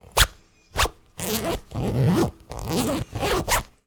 Cloth Jeans Zipper Sound
household